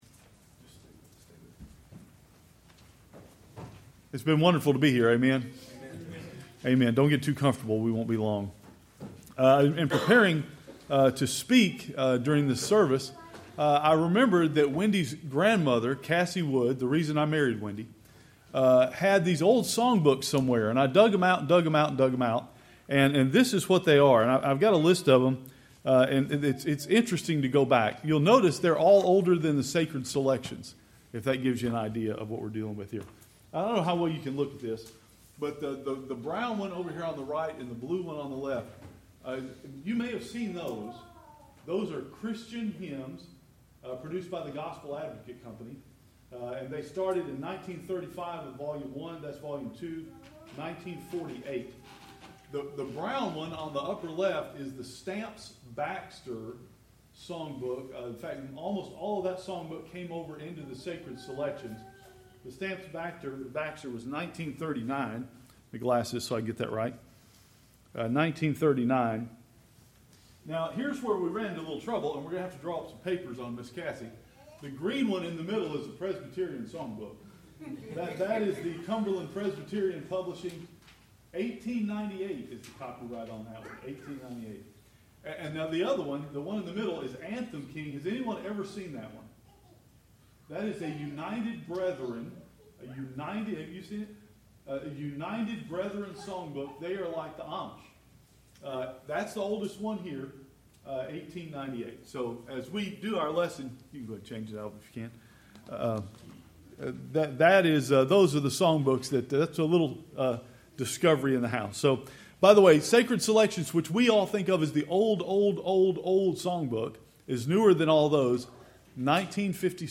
A Night of Scripture and Praise (Sermon)
Congregational Singing